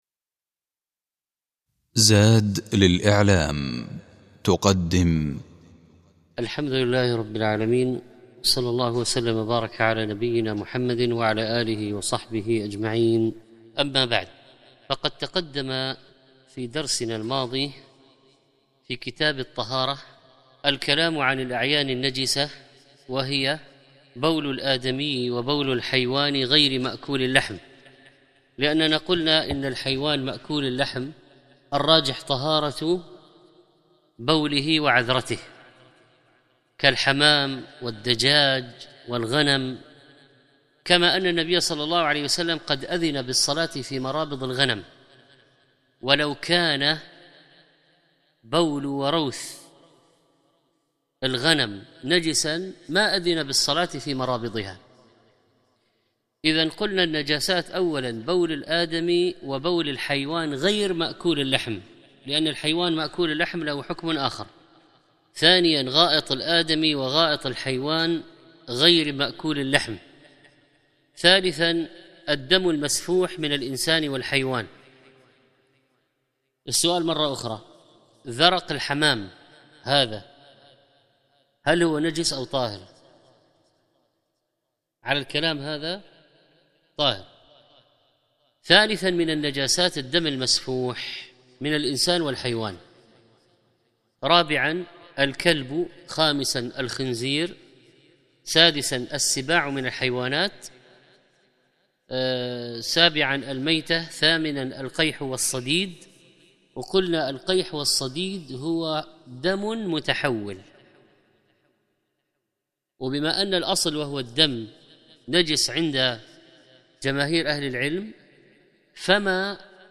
أحكام النجاسة - الدرس التاسع